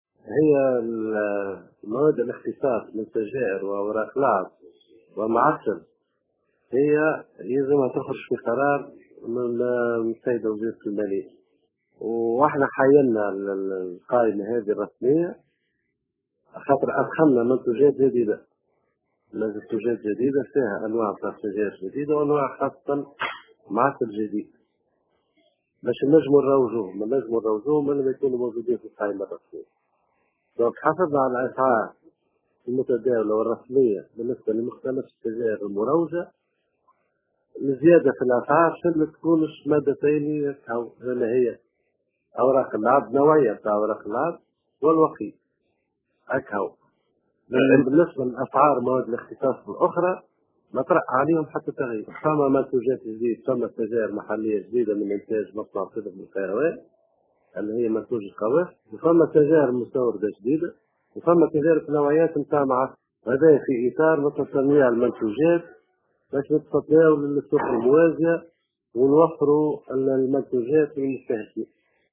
نفى مدير عام الوكالة الوطنية للتبغ والوقيد، عماد عطية، في تصريح خاص بالجوهرة اف أم، اليوم الثلاثاء، صحة الأخبار المتداولة بخصوص الزيادة في أسعار السجائر، ومواد الاختصاص، كأوراق اللعب والمعسل.